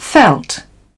felt /1/ /felt/ /felt/